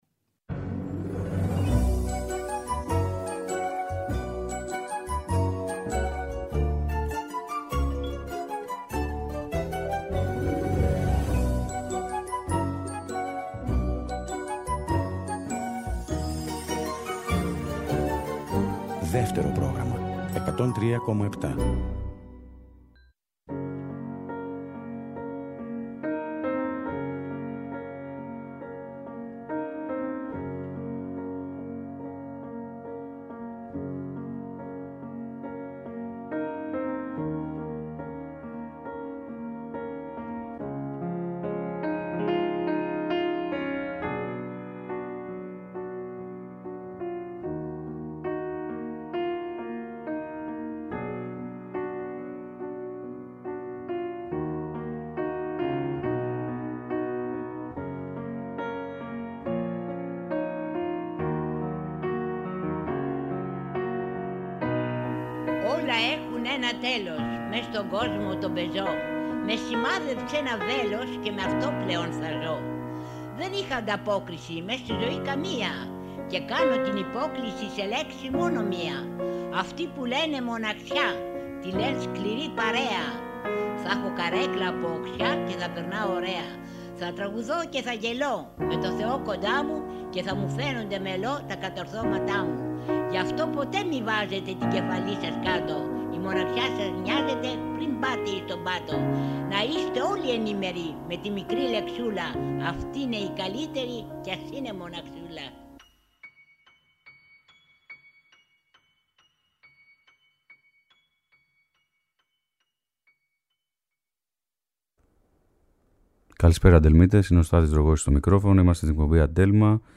για φωνή και πιάνο